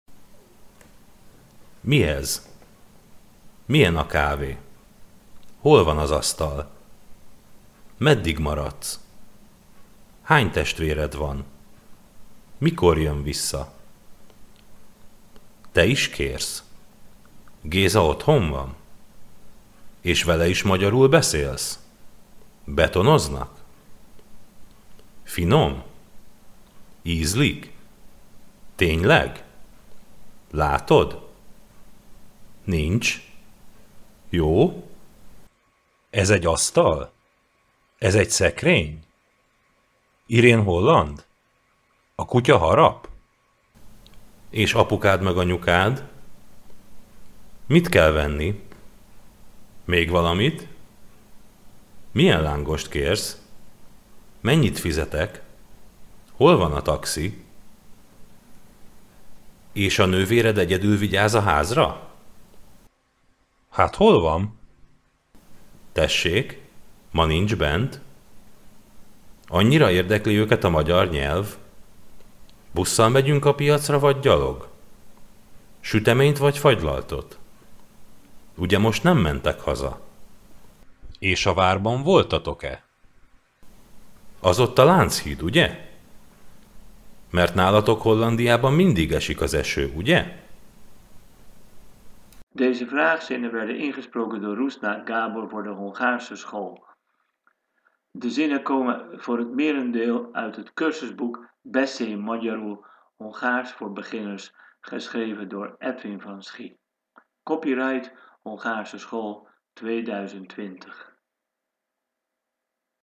Deel 1: de drie basisvraagmelodiepatronen
Audio bij deze handout over de Hongaarse vraagmelodie: